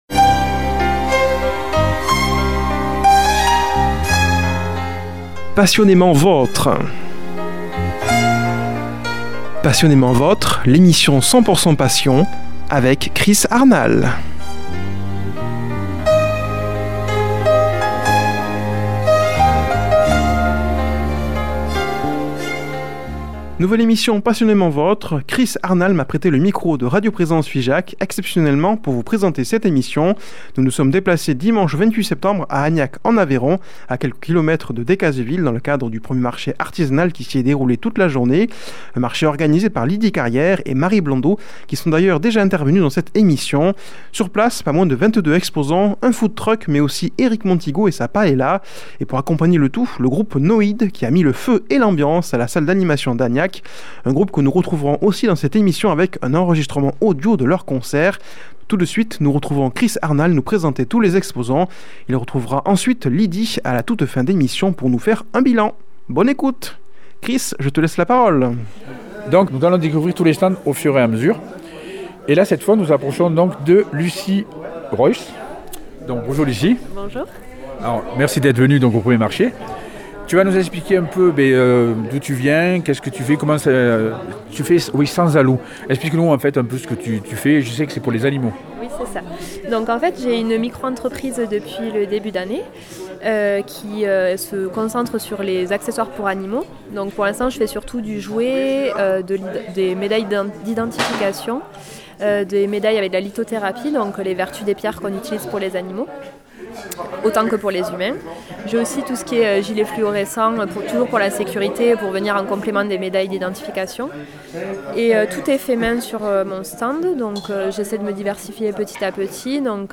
Il a pu aussi enregistrer une partie du concert du groupe NOID que nous retrouverons pour la pause musicale.